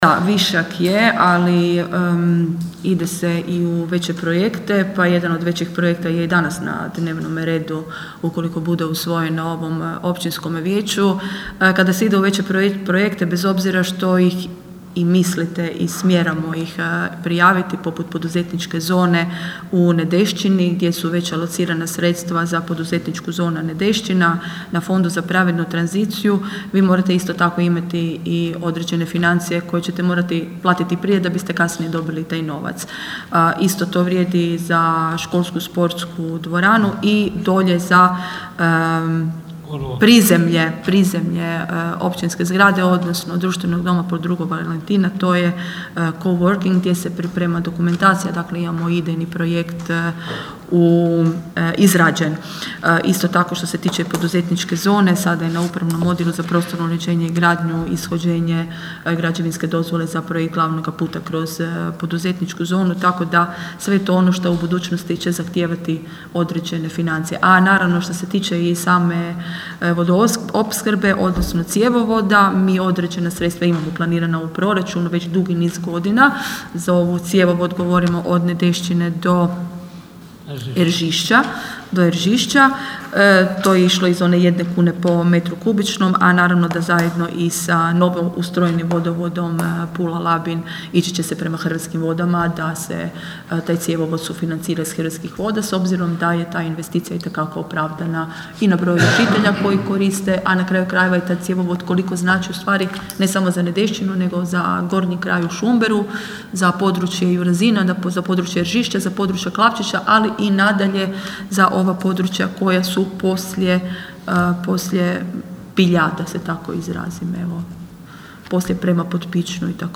Odgovorila je načelnica Irene Franković: (